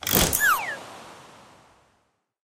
zoom_in_v01.ogg